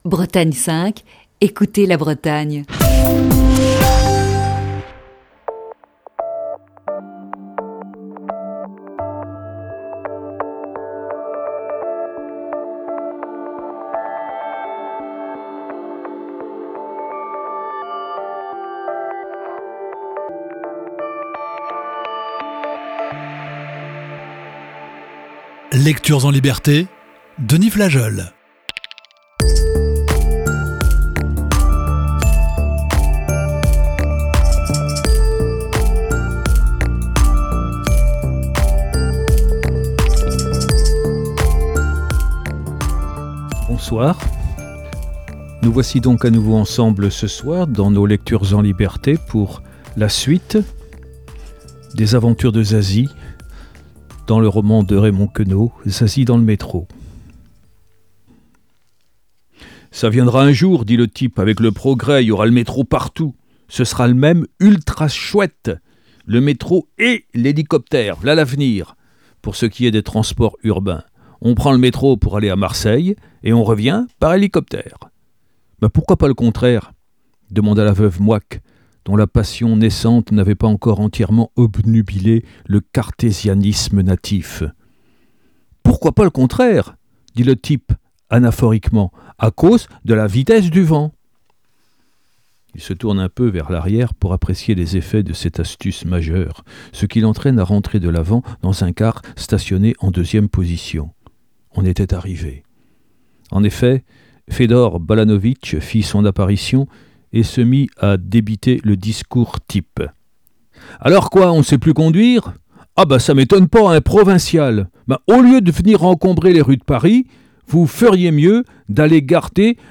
Émission du 8 avril 2020.